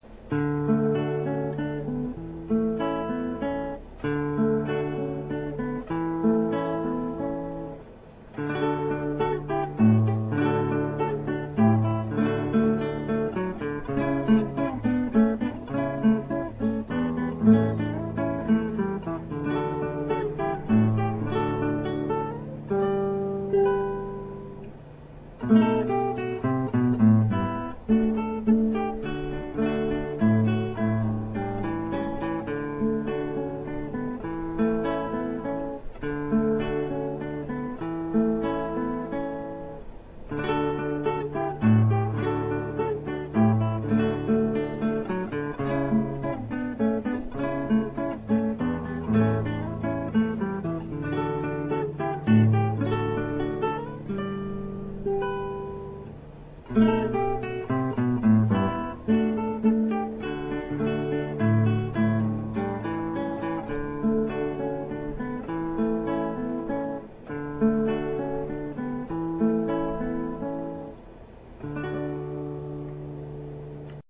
Real Audio Format (Myself Playing).
A lovely piece, really espressive.
transcribed for guitar